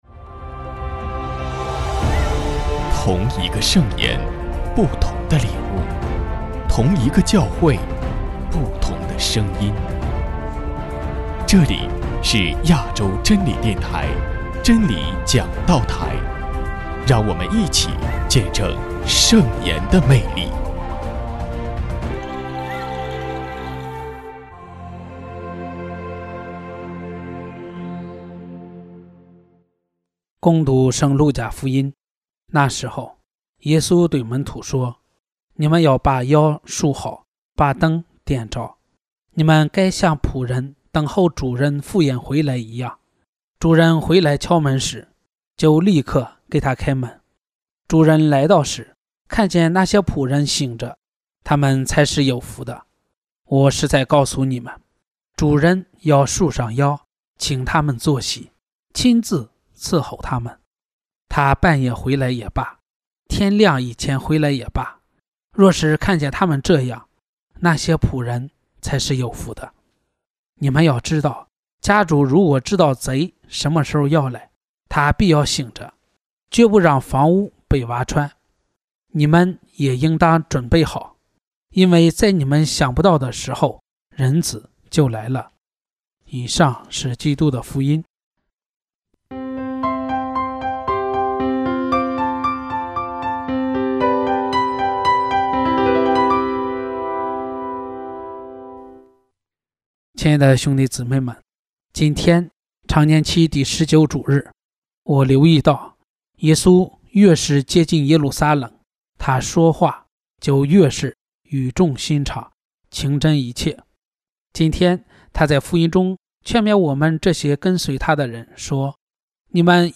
首页 / 真理讲道台/ 证道/ 丙年